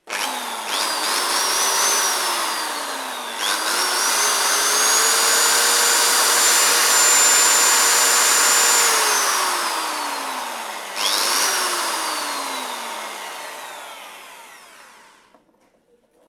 Taladradora
taladro
Sonidos: Industria